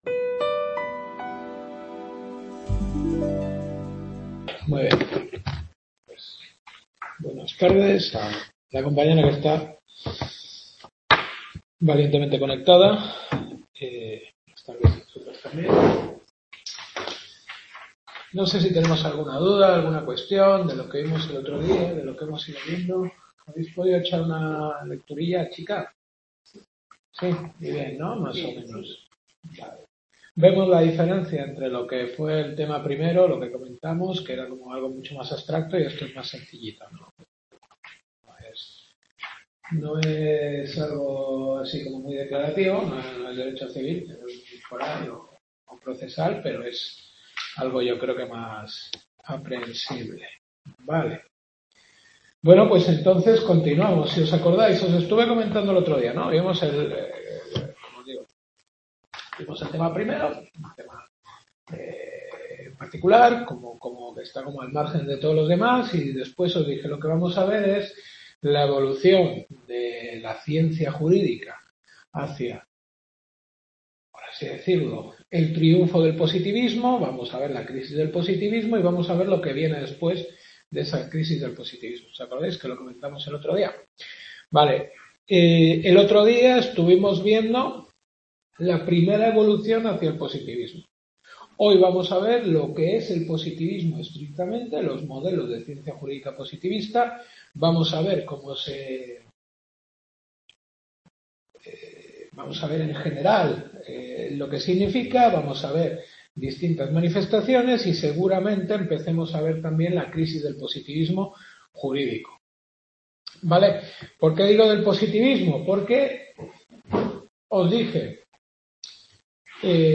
Filosofía del Derecho. Tercera Clase.